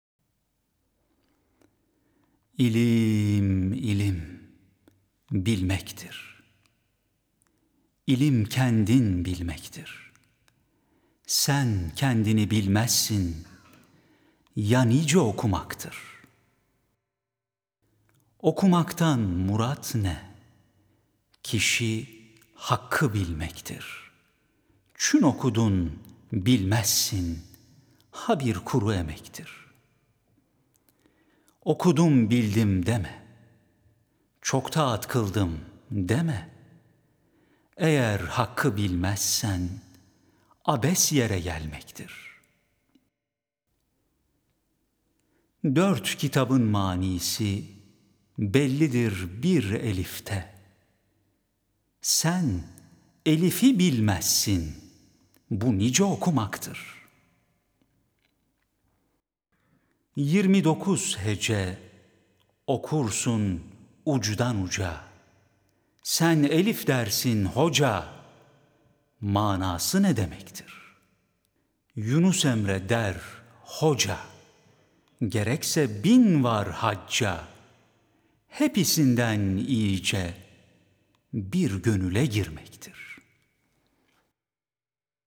Vaktiyle, bu şiiri seslendirmek bize de nasip olduğu için, konuyu tamamlamak ve buradaki doğru okuyuşu örnekleyebilmek maksadıyla şiiri bir de bizden dinlemenizi rica ediyorum..